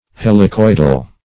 Helicoidal \Hel`i*coid"al\, a.